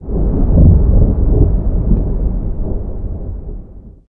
thunder37.ogg